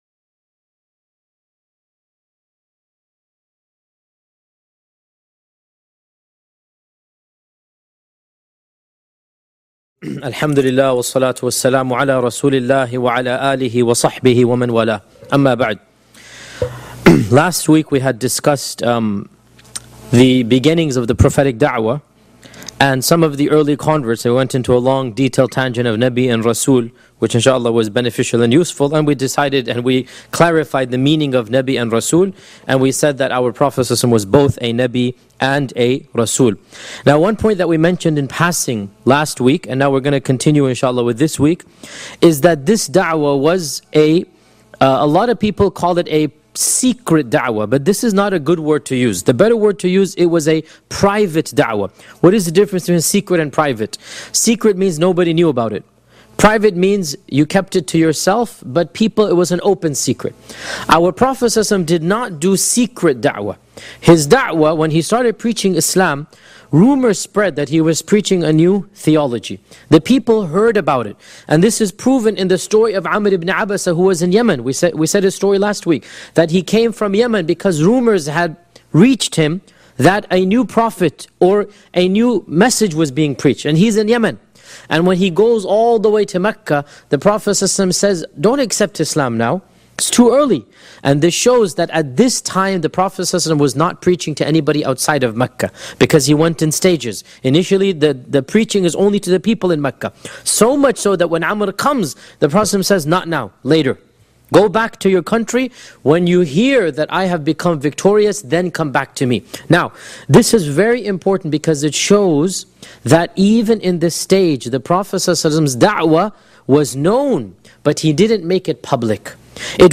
525 views High Quality: Download (284.13 MB) Medium Quality: Download (45.42 MB) MP3 Audio (01:17:15): Download (58.6 MB) Transcript: Download (0.33 MB) Seerah of Prophet Muhammad 11 Shaykh Yasir Qadhi gives a detailed analysis of the life of Prophet Muhammed (peace be upon him) from the original sources. Title: Stages of Da’wah & Declaration of Prophethood Study the biography of the single greatest human being that ever walked the surface of this earth, whom Allah sent as a Mercy to Mankind. This lecture was recorded on 19th October, 2011 Shaykh Yasir Qadhi gives a detailed analysis of the life of Prophet Muhammed (peace be upon him) from the original sources.